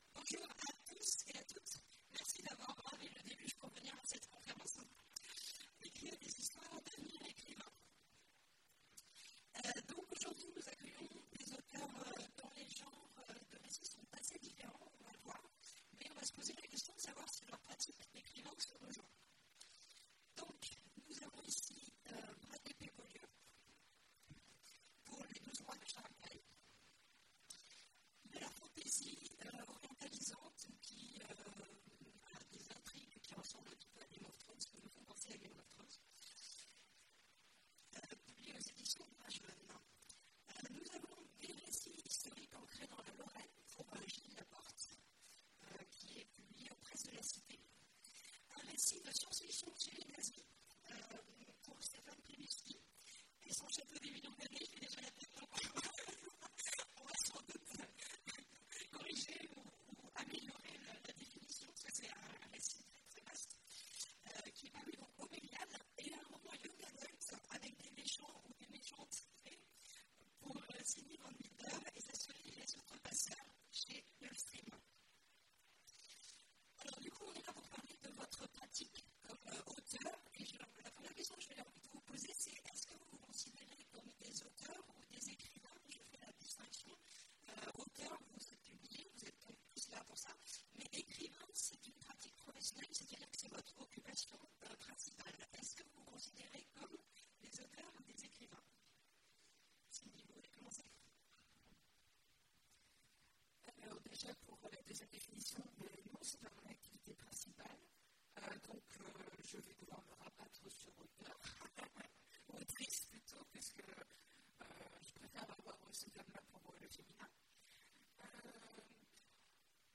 Mots-clés Conférence Partager cet article